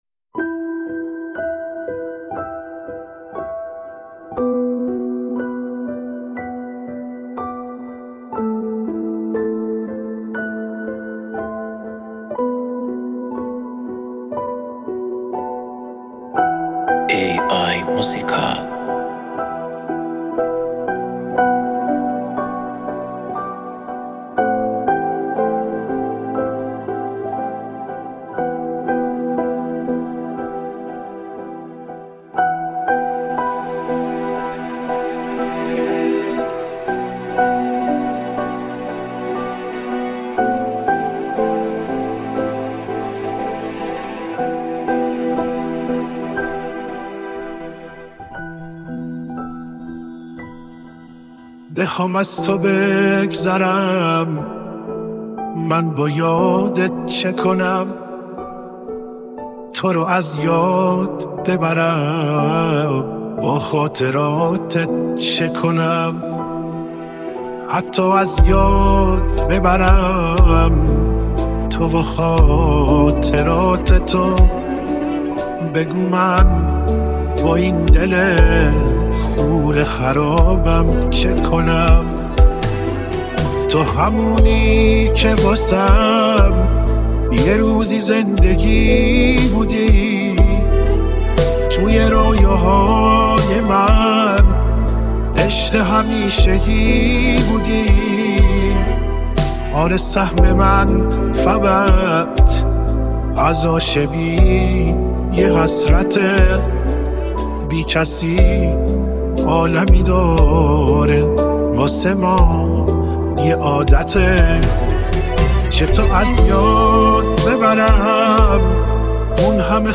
– حسرت و بی کسی در یک ملودی سوزناک